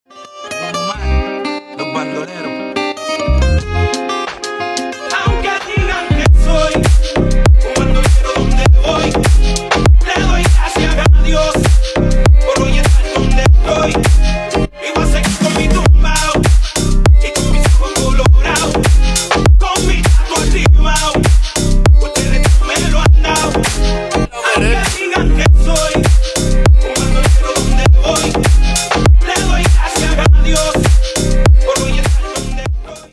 громкие
латинские , новые , ремиксы , рэп , хип-хоп